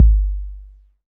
Tuned kick drum samples Free sound effects and audio clips
• Huge Kick Sound G Key 104.wav
Royality free kick drum tuned to the G note. Loudest frequency: 70Hz
huge-kick-sound-g-key-104-pes.wav